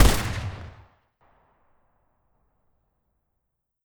AR2_Shoot 05.wav